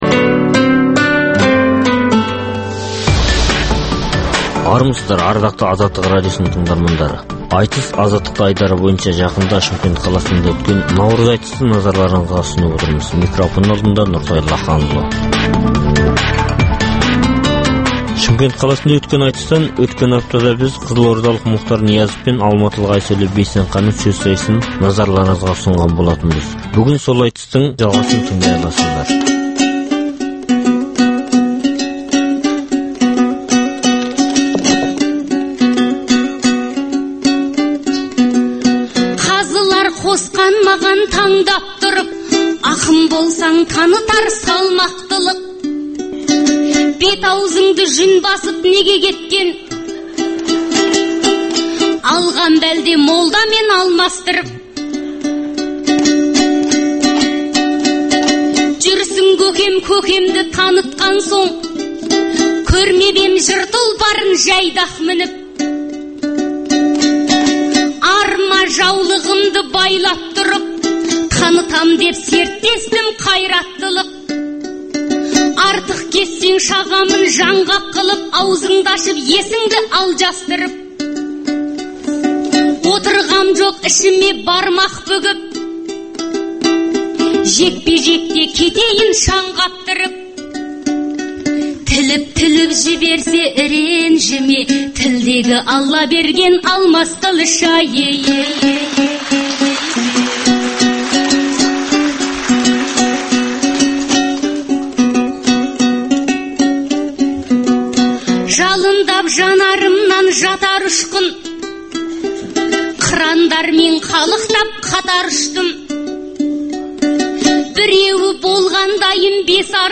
Айтыс - Азаттықта
Қазақстанда әр уақытта өткізілетін ақындар айтысының толық нұсқасын ұсынамыз.